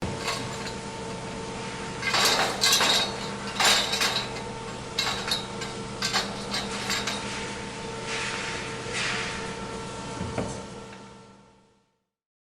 Metal Shuffle
Metal Shuffle is a free sfx sound effect available for download in MP3 format.
yt_FU6KgVc6C70_metal_shuffle.mp3